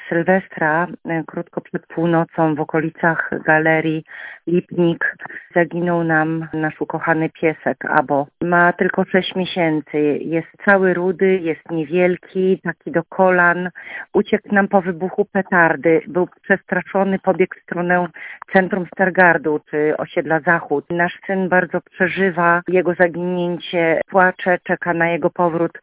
Jedną z takich historii przekazała słuchaczka Twojego Radia.
STAR-Sluchaczka-FajerwerkiPies.mp3